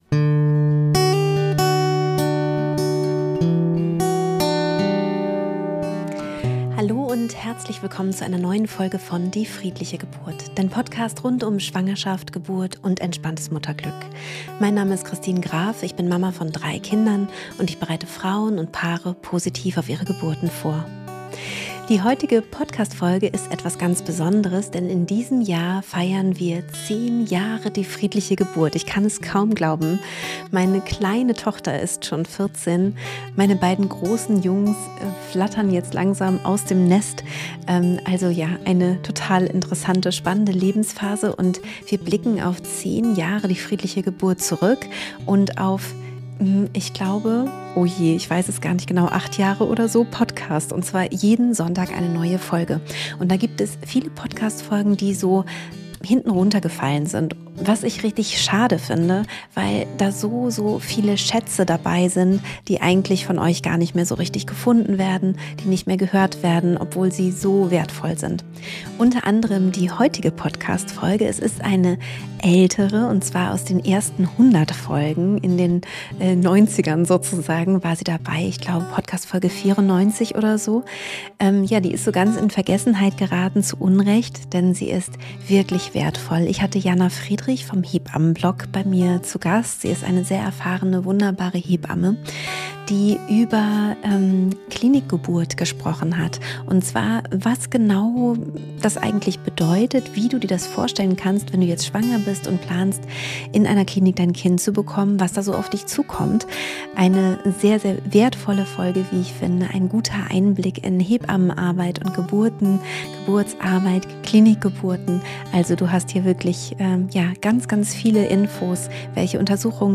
438 – Was muss ich wissen für eine Klinikgeburt? – Interview